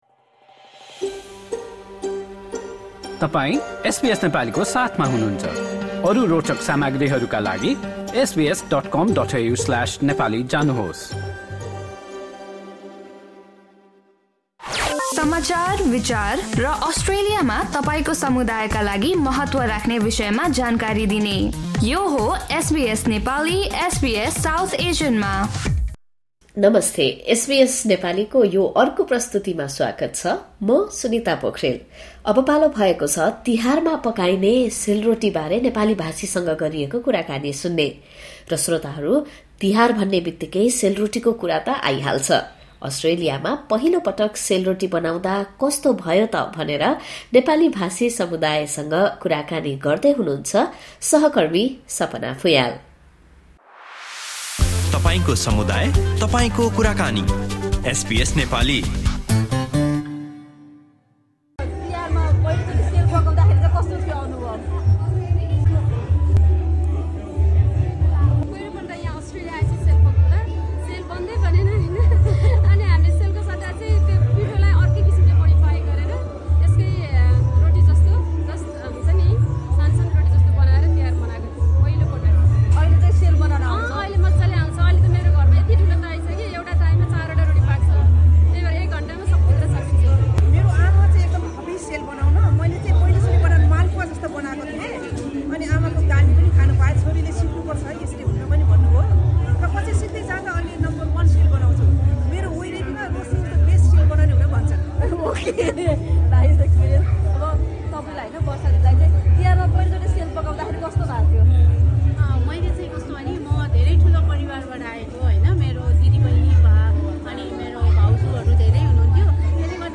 तिहार भन्ने बित्तिकै सेल रोटीको कुरा त आइहाल्छ। मेलबर्नका केही नेपाली भाषीहरूले अस्ट्रेलियामा पहिलो पटक सेल रोटी पकाउँदाको अनुभवबारे एसबीएस नेपालीसँग गरिएको कुराकानी सुन्नुहोस्।
Nepali community members in Melbourne share their first Sel Roti making experience.